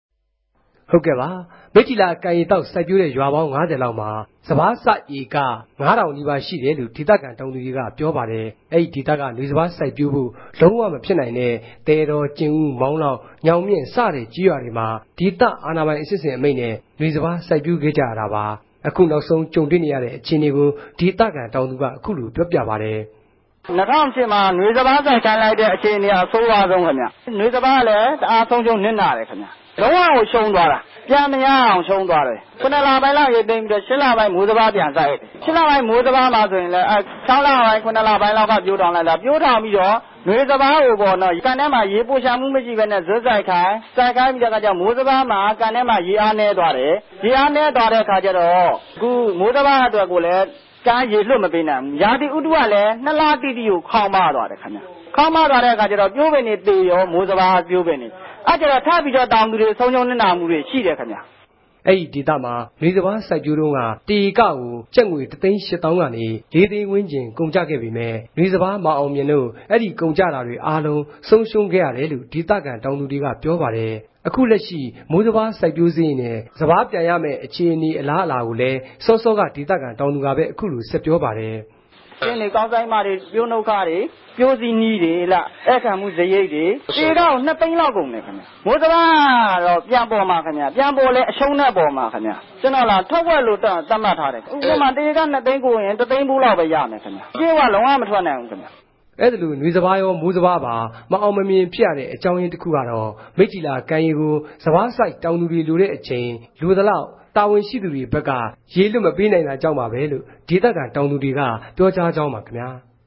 သတင်းပေးပိုႛခဵက်